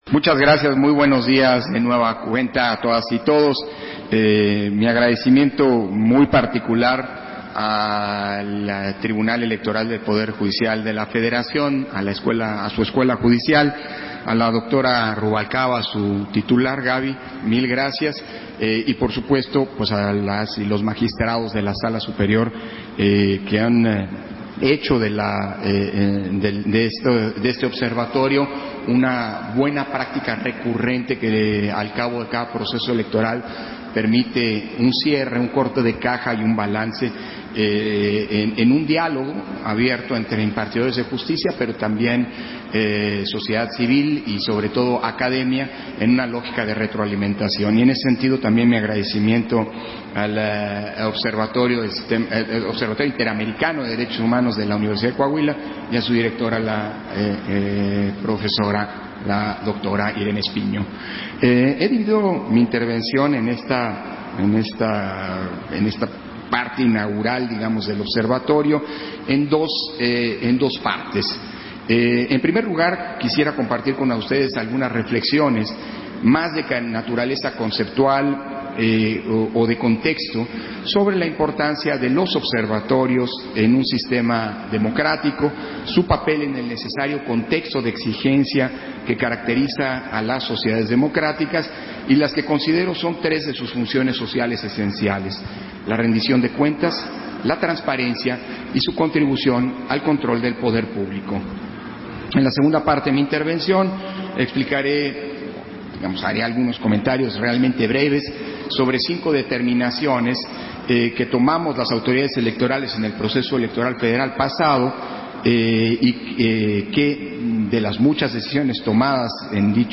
230622_AUDIO_INTERVENCIÓN-CONSEJERO-PDTE.-CÓRDOVA-OBSERVATORIO-JUDICIAL-ELECTORAL-DIÁLOGO-INAUGURAL-1 - Central Electoral